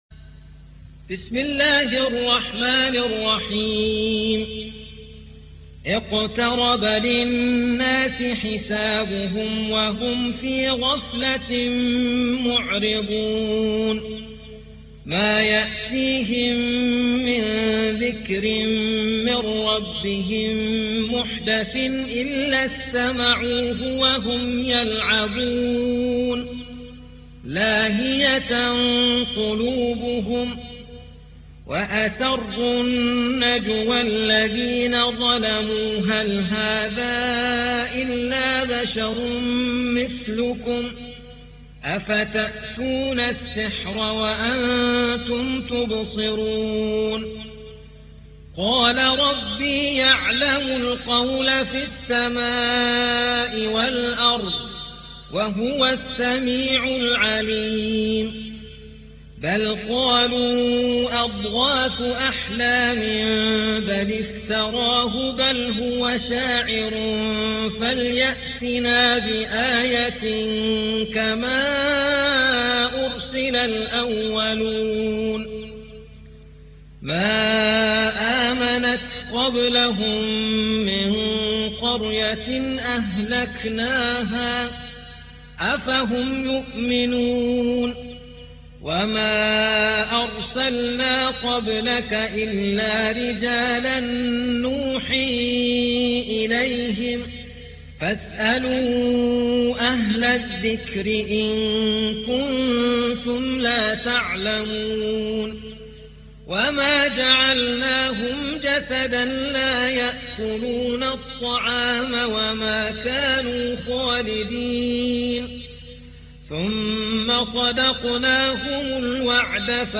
21. سورة الأنبياء / القارئ